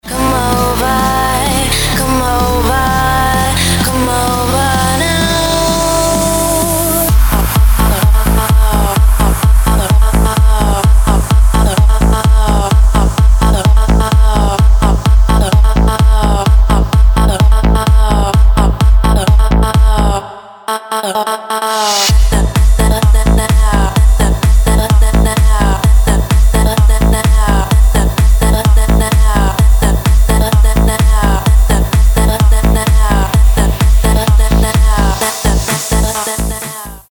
• Качество: 256, Stereo
dance
club
house
electro house
dutch house